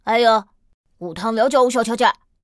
c01_4残疾小孩_2.ogg